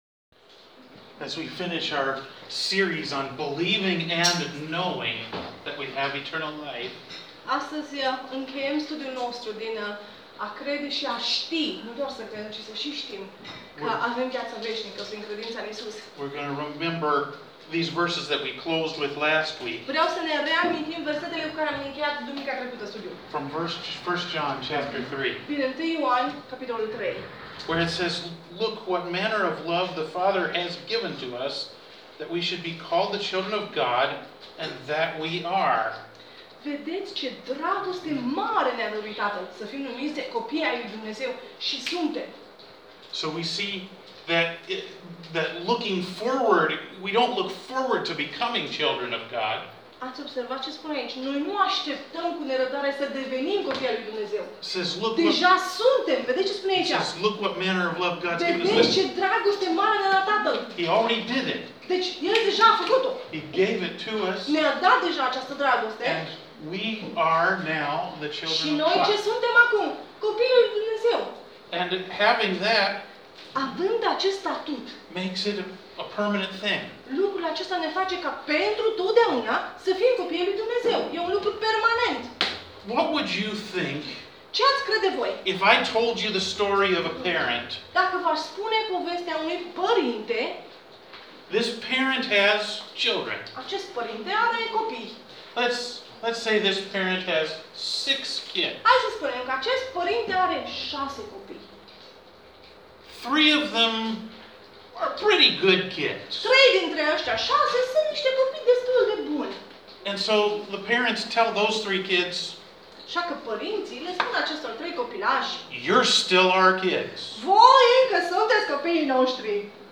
Sermon Audio mp3, Duminica 4 noiembrie 2018